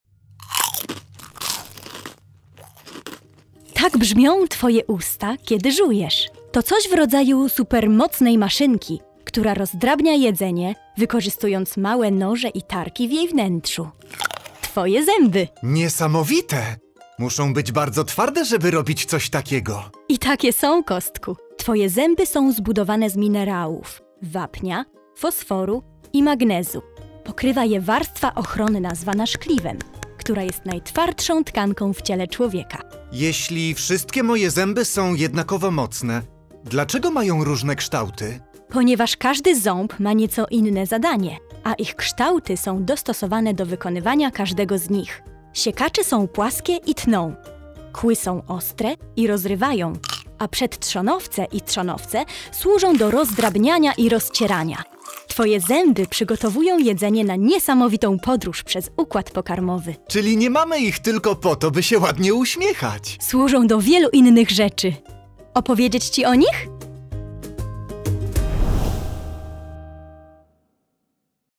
Odkryj zabawne rozmowy, pełne ciekawostek i odgłosów Twojego organizmu.
Posłuchaj, jak brzmią twoje usta, kiedy żujesz